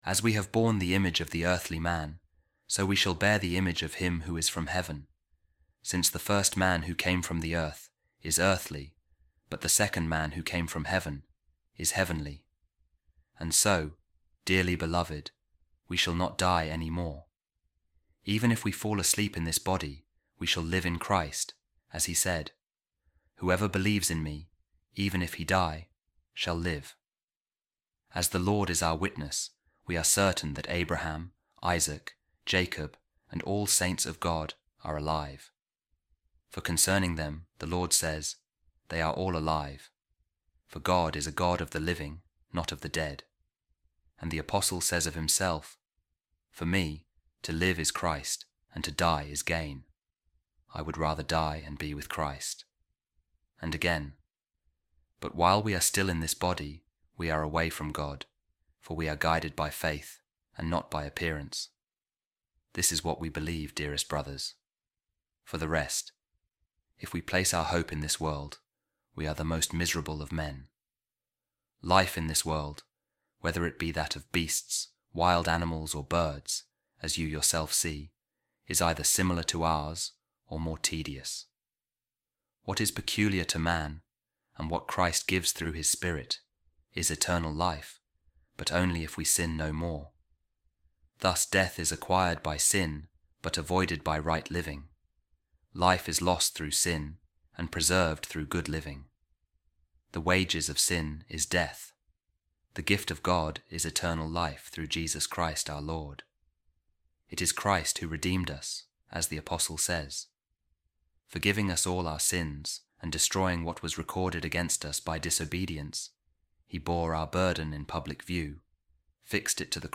A Reading From The Sermon Of Saint Pacian On Baptism | Oh My God, Oh My Jesus | You Take Away Sin